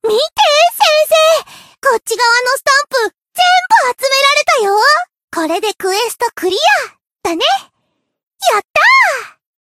贡献 ） 分类:蔚蓝档案 ； 分类:蔚蓝档案语音 ；协议：Copyright 您不可以覆盖此文件。